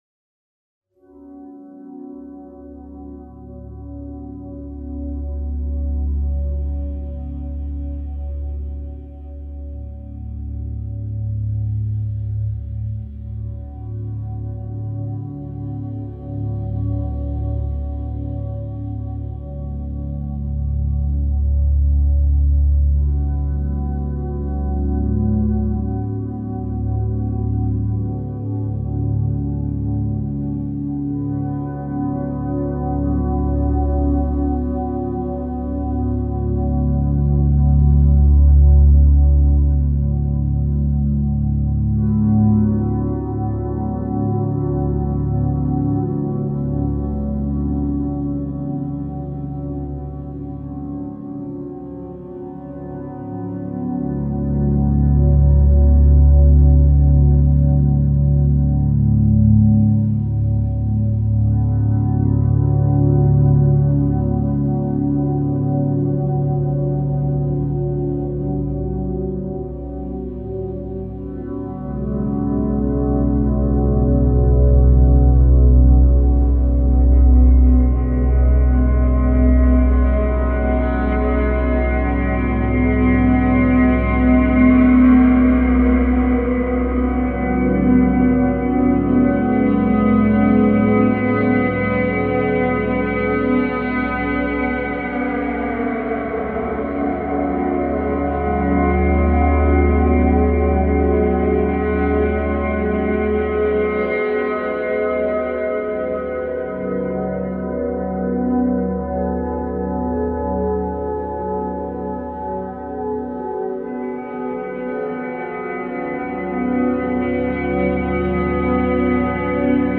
File under: Experimental / Ambient
sviluppi ambient ora esoterici